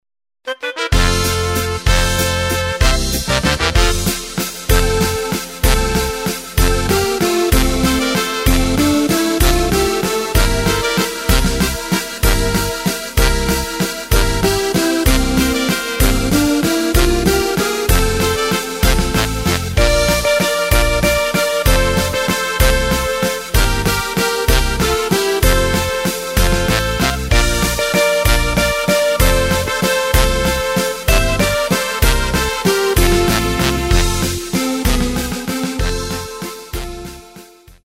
Takt:          3/4
Tempo:         191.00
Tonart:            F
Walzer - Polka Medley zum Feiern!